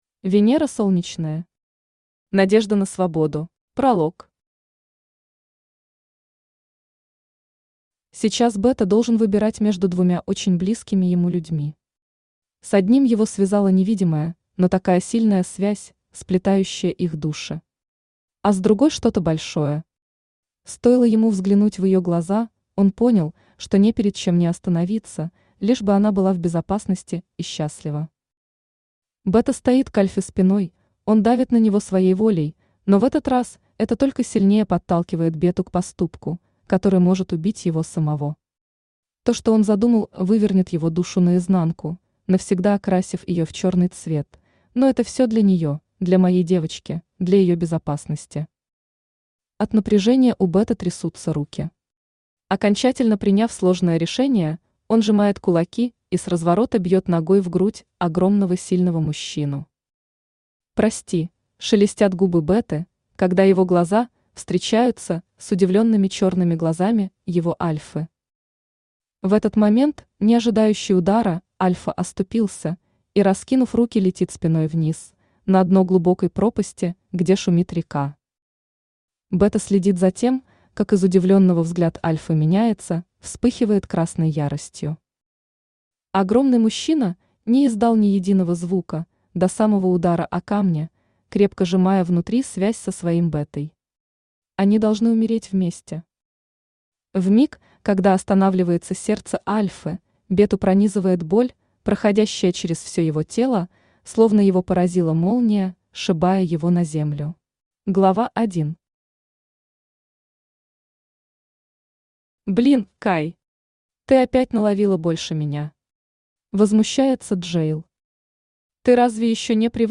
Аудиокнига Надежда на свободу | Библиотека аудиокниг
Aудиокнига Надежда на свободу Автор Венера Солнечная Читает аудиокнигу Авточтец ЛитРес.